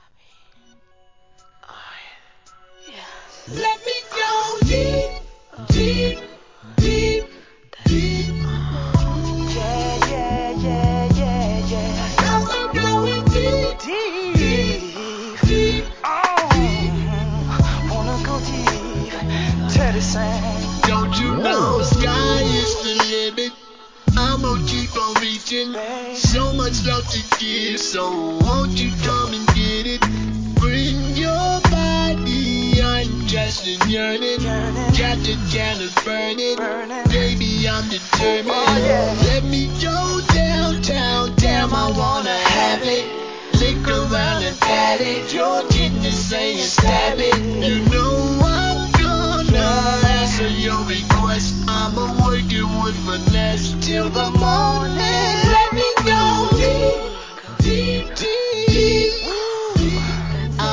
HIP HOP/R&B
タイトル通り、あま〜いSLOW JAMばかりを集めた歌モノ好きには嬉しいコンピ第4弾!!!